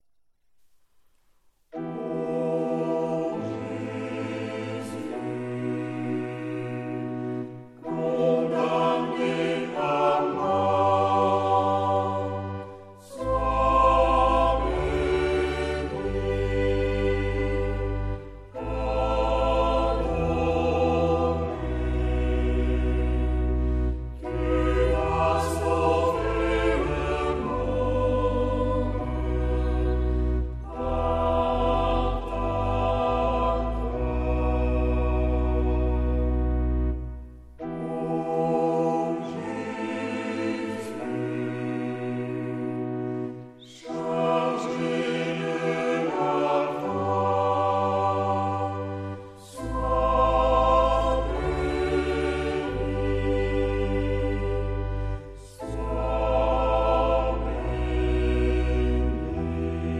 Genre-Style-Form: Sacred ; Acclamation
Type of Choir: SATB  (4 mixed voices )
Tonality: G major